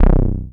07 Synther 7 B.wav